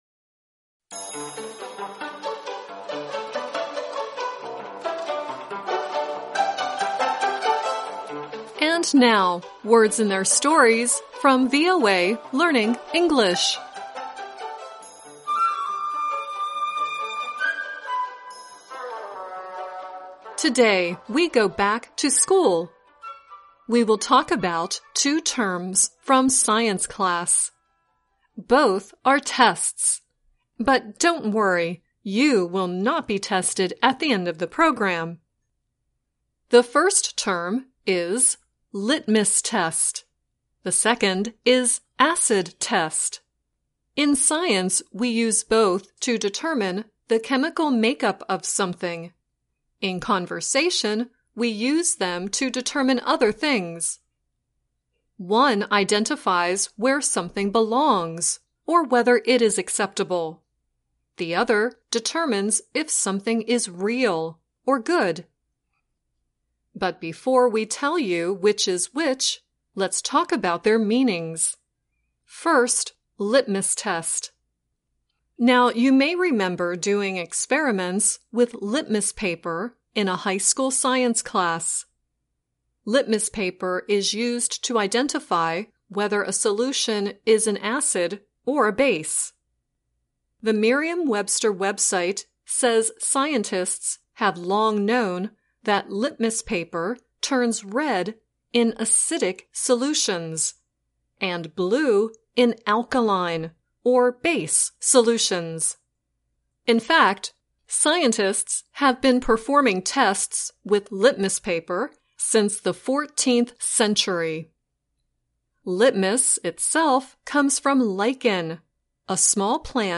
The song at the end is Jackson Browne singing “About My Imagination.”